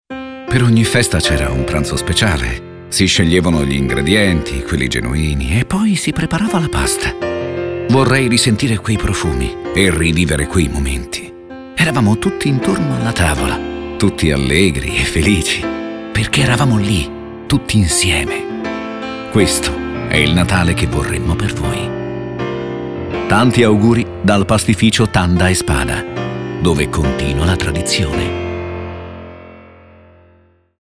Spot Auguri Natale